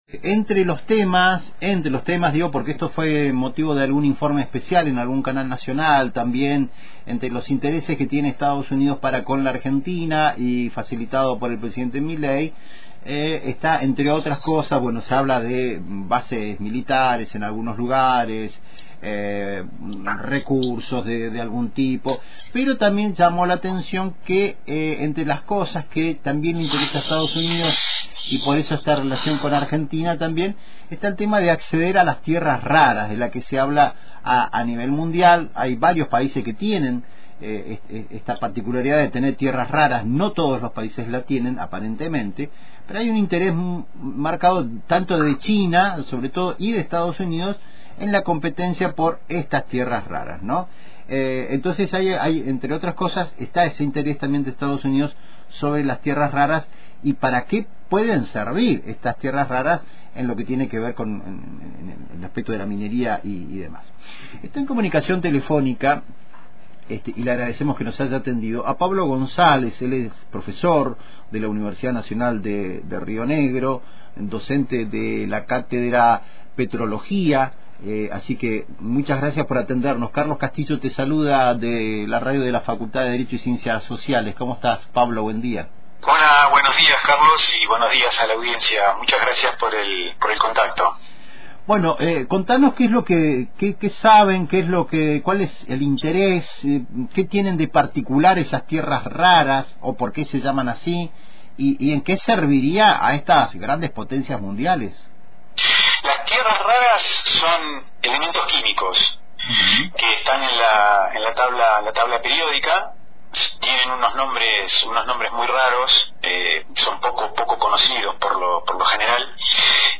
explicó en diálogo con Radio Antena Libre el creciente interés de Estados Unidos en las tierras raras argentinas, elementos químicos esenciales para la tecnología moderna y la transición energética.